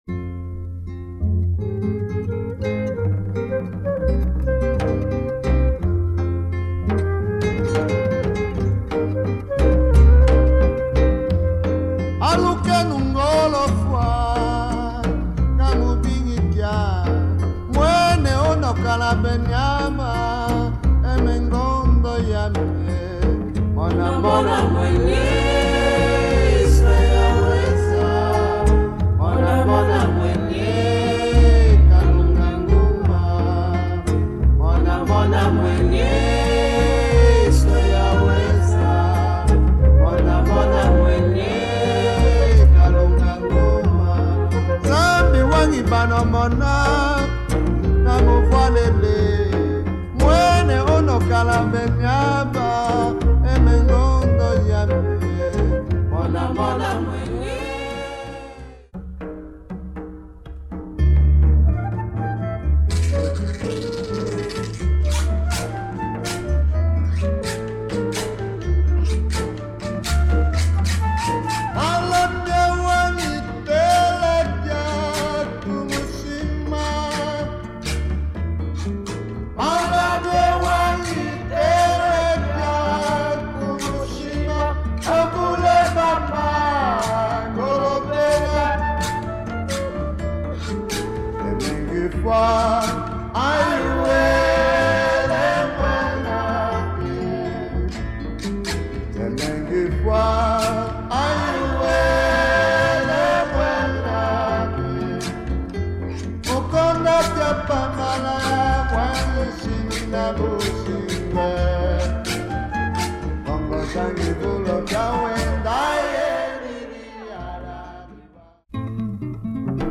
Beautiful music from Angola
Wonderful music and chants !